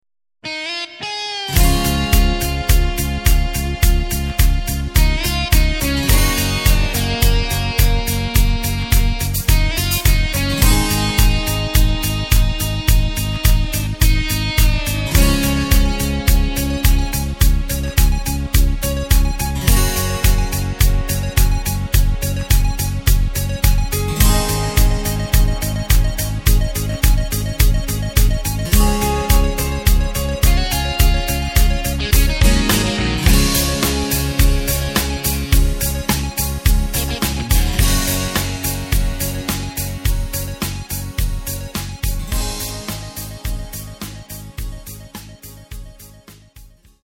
Takt: 4/4 Tempo: 106.00 Tonart: Db
mp3 PlaybackDemo